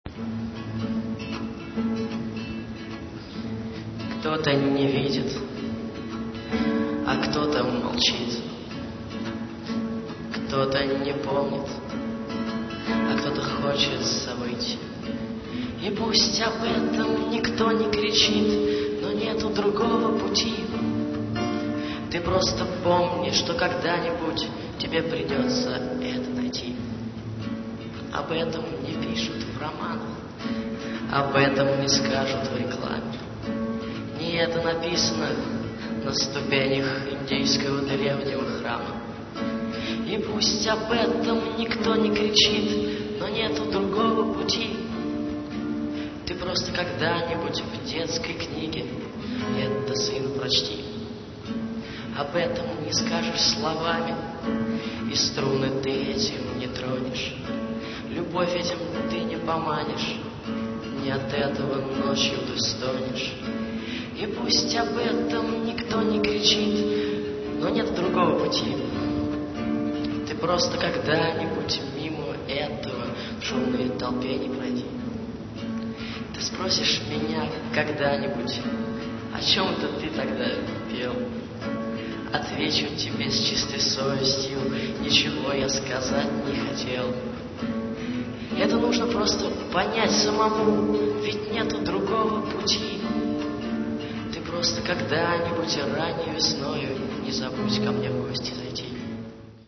291 kb, авторское исполнение